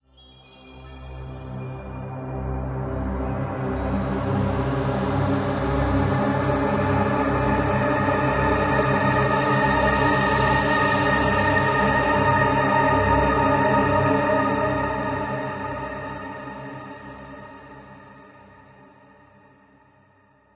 戏剧性的合唱 pads " 戏剧性的pads 4
描述：这首曲子的特点是：深沉、黑暗、戏剧性，有很多不和谐的音符。从低处开始，向上建立到黑暗的天空。